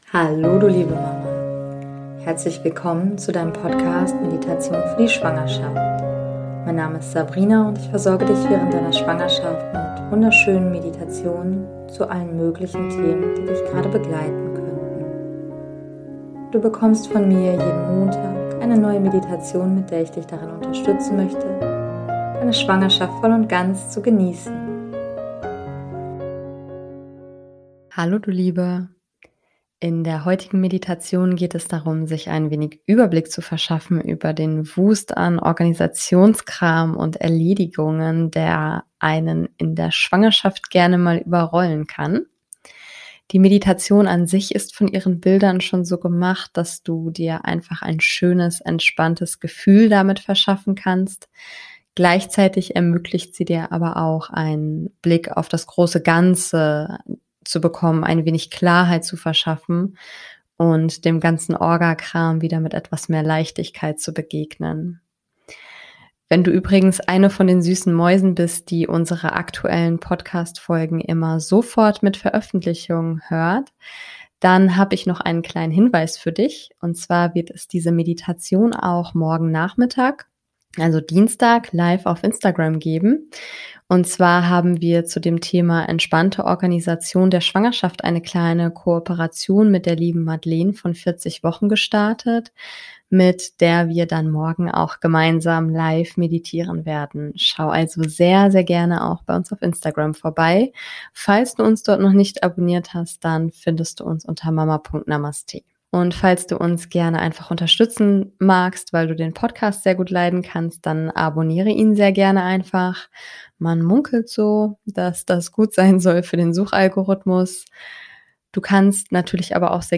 Beschreibung vor 4 Jahren In der heutigen Meditation geht es darum, sich ein wenig Überblick zu verschaffen, über den Wust an Organisationskram und Erledigungen, der einen in der Schwangerschaft gerne mal überrollen kann. Die Meditation an sich ist von ihren Bildern schon so gemacht, dass du dir einfach ein schönes entspannendes Gefühl verschaffen kannst. Gleichzeitig ermöglicht sie dir aber auch einen Blick auf das Große Ganze, dir ein wenig Klarheit zu verschaffen und dem ganzen Orgakram wieder mit etwas mehr Leichtigkeit zu begegnen.